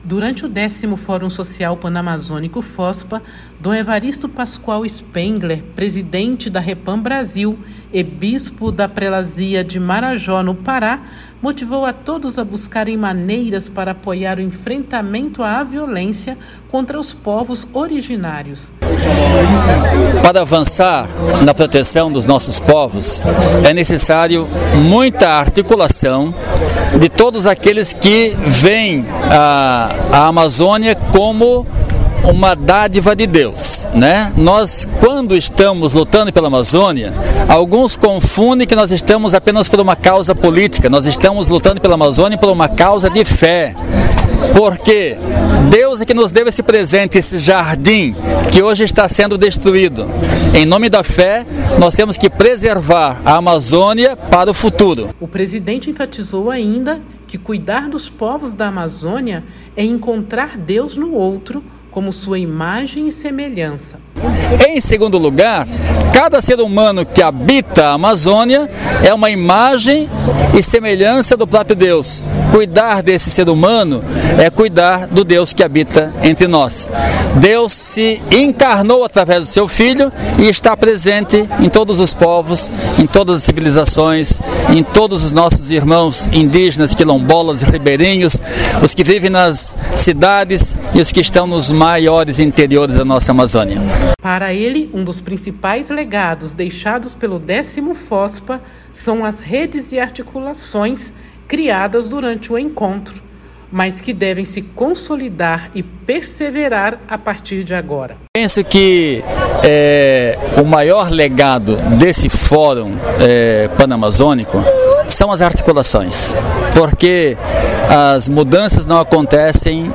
Durante o X Fórum Social Pan-Amazônico (FOSPA), Dom Evaristo Pascoal Spengler, presidente da REPAM Brasil e bispo da Prelazia de Marajó, PA,  motivou todos a buscarem maneiras para apoiar o enfrentamento à violência contra os povos originários.